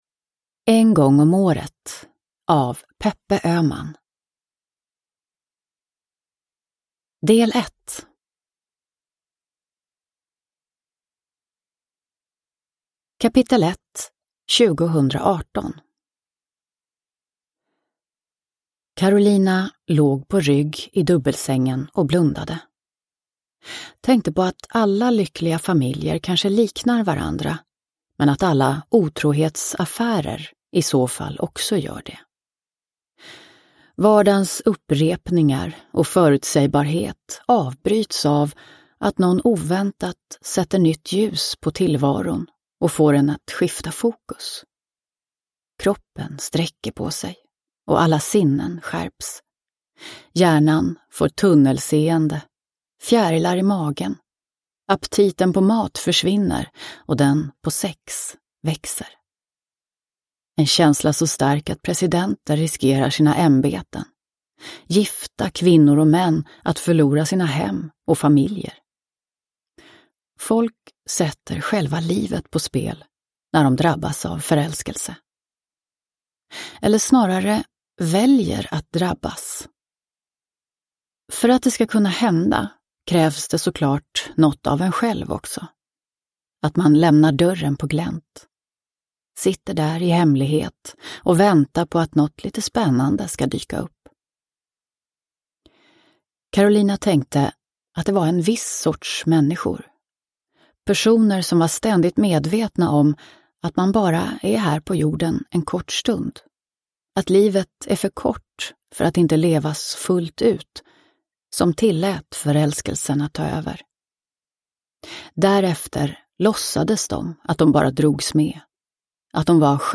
En gång om året – Ljudbok – Laddas ner